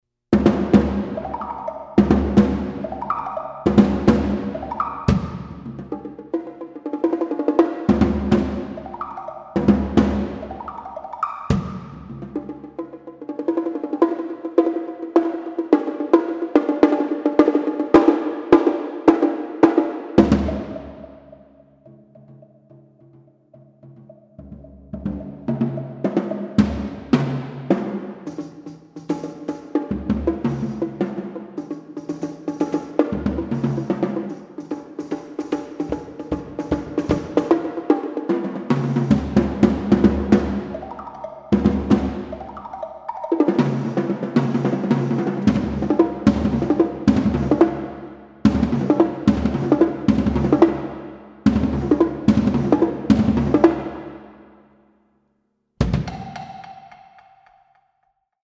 modern classical music for percussion
multiple percussion solo